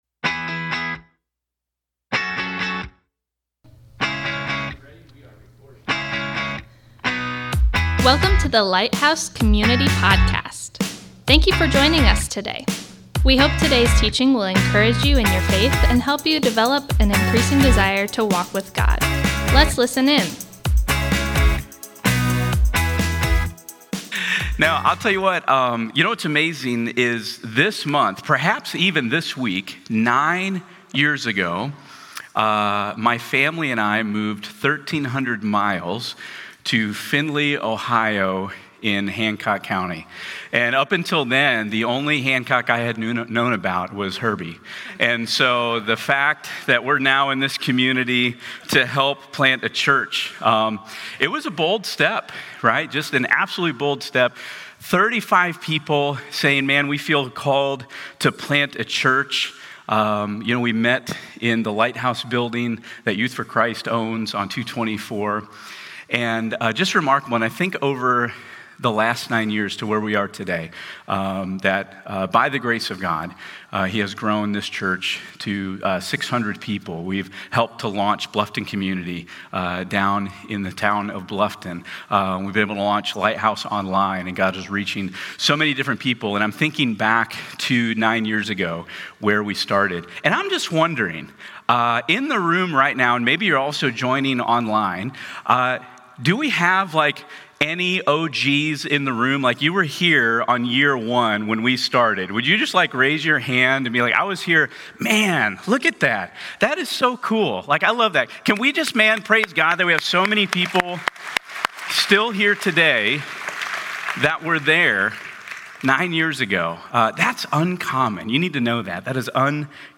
Thank you for joining us today as we come together to worship!